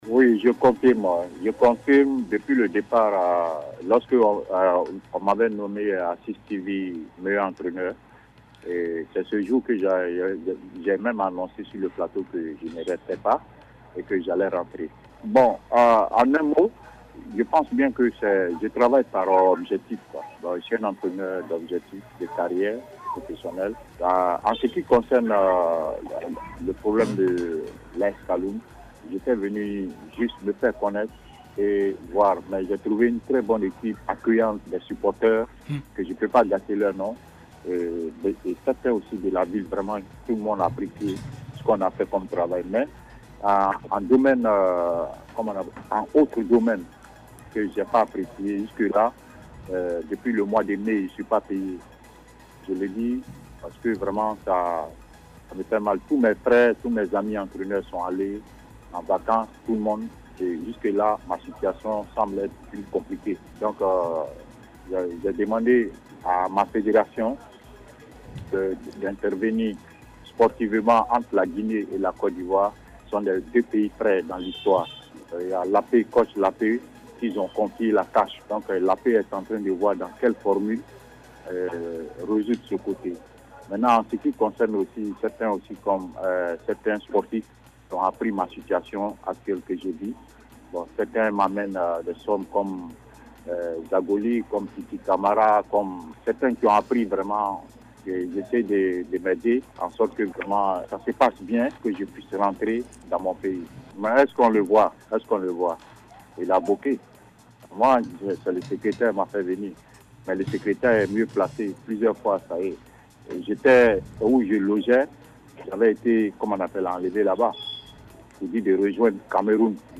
Le technicien ivoirien l’a lui-même confirmé sur les antennes de Cis Radio.